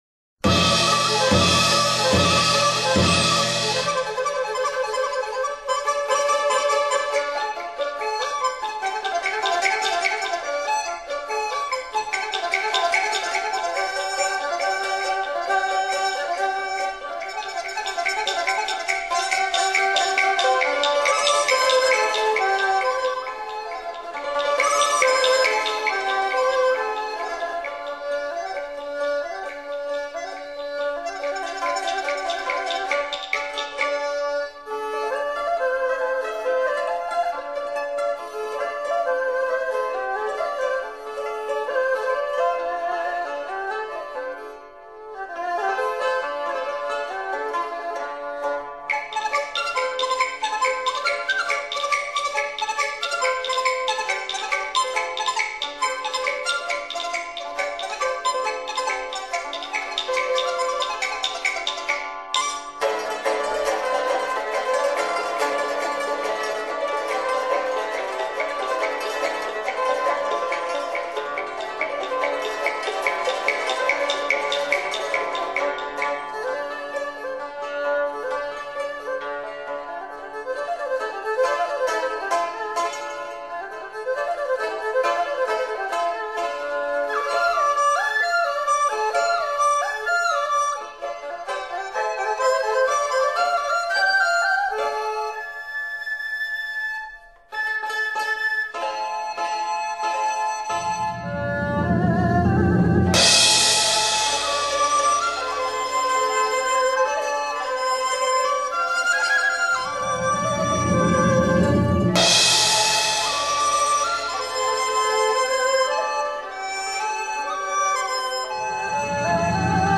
其栩栩如生的音场，毫发毕现的细节，干净利落的瞬态，堪称民乐中之极品。
通过不同乐器描绘蒙古民族传统的马术比赛。